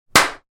Звук мощного хлопка ладонями двух парней